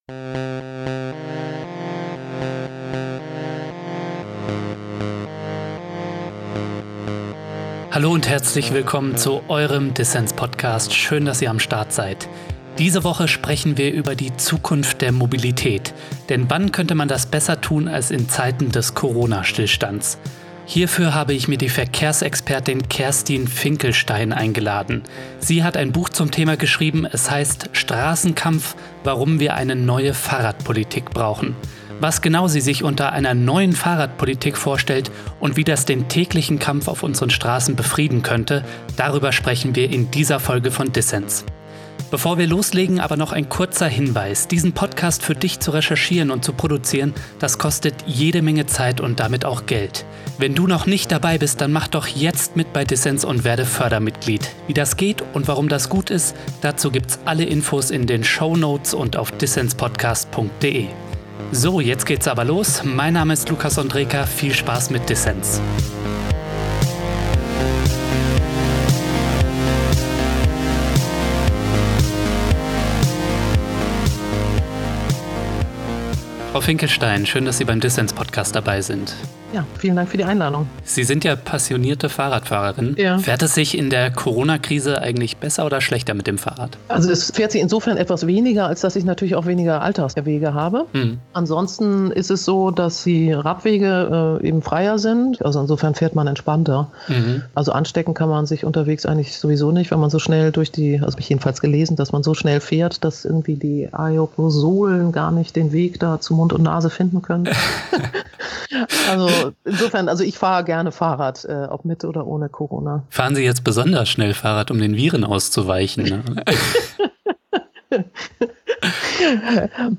Ein Gespräch über Corona-Prämien beim Fahrradkauf, autofreie Innenstädte und Selbstverteidigung auf dem Fahrrad.